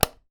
switch_on.wav